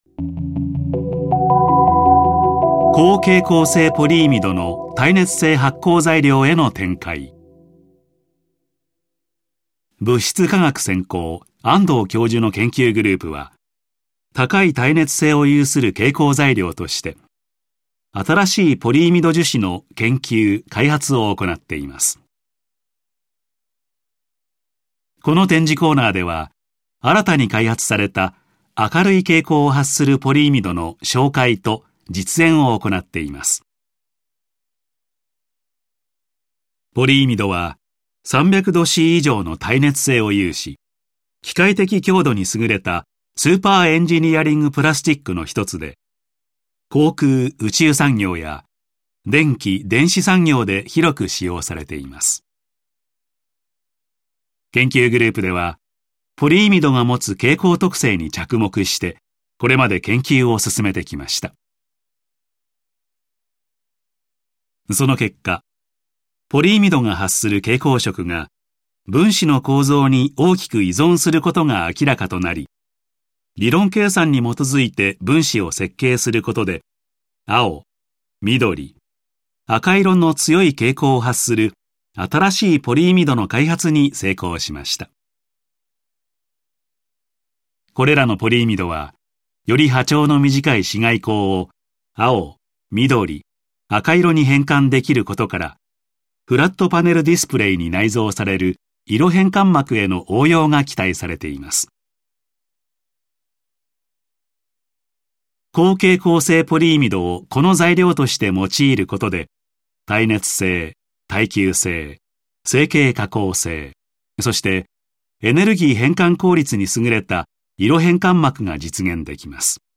音声による"高蛍光性ポリイミド"のご紹介（mp3）